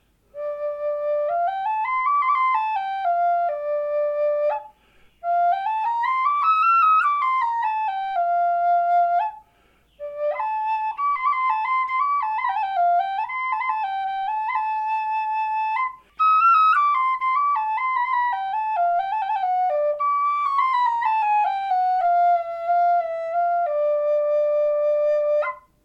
D5 in Black Walnut with a Spalted Hackberry sweeptail bird. ADOPTED!